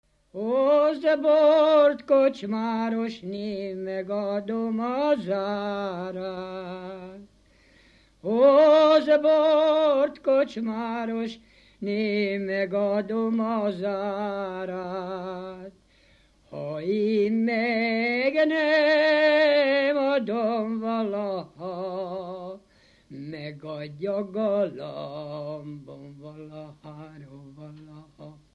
Dunántúl - Somogy vm. - Zákány
ének
Stílus: 5. Rákóczi dallamkör és fríg környezete